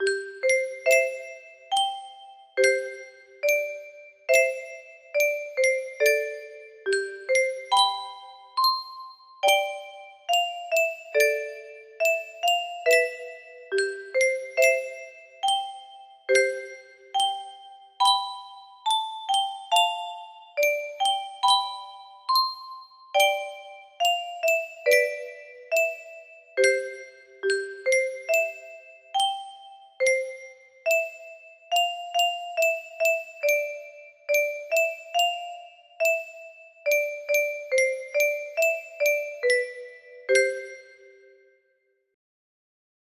Lullaby music box melody